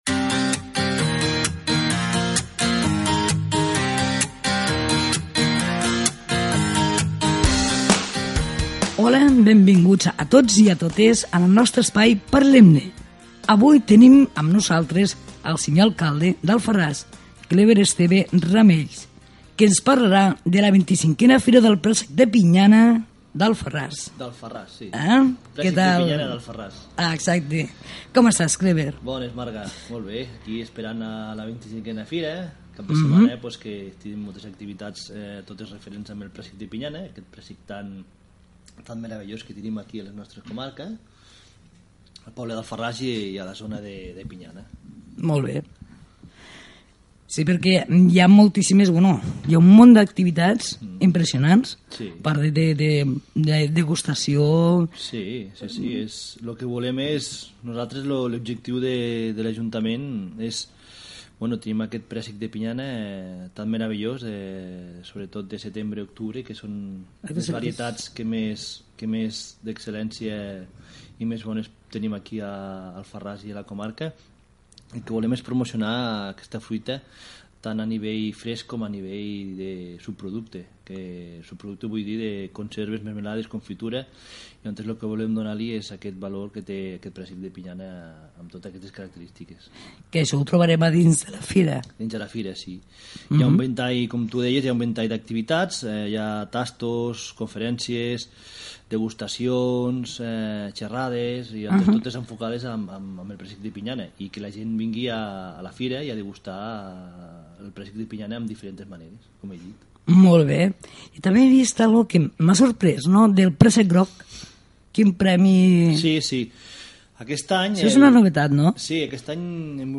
Entrevista a l'alcalde d'Alfarràs, Kleber Esteve, sobre la 25a Fira del Préssec de Pinyana